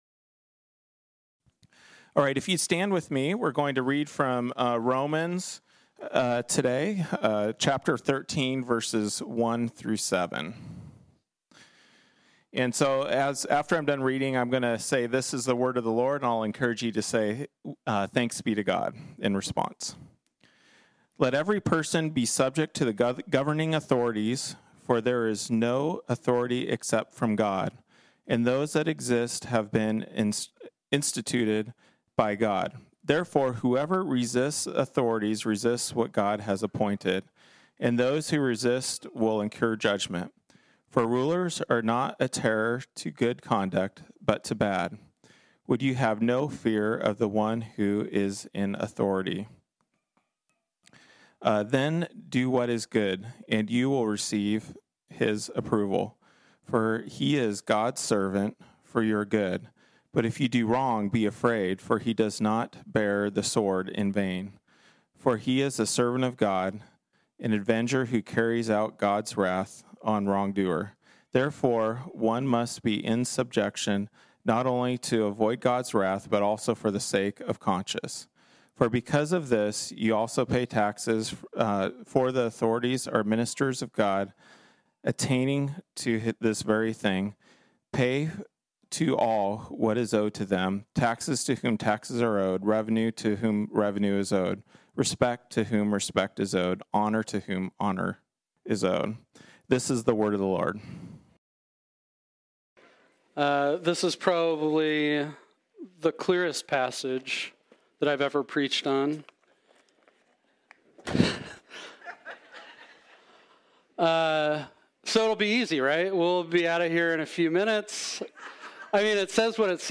This sermon was originally preached on Sunday, July 18, 2021.